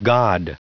Prononciation du mot god en anglais (fichier audio)
Prononciation du mot : god